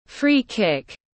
Đá phạt trực tiếp tiếng anh gọi là free kick, phiên âm tiếng anh đọc là /ˌfriː ˈkɪk/
Để đọc đúng đá phạt trực tiếp trong tiếng anh rất đơn giản, các bạn chỉ cần nghe phát âm chuẩn của từ free kick rồi nói theo là đọc được ngay.